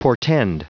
Prononciation du mot portend en anglais (fichier audio)
Prononciation du mot : portend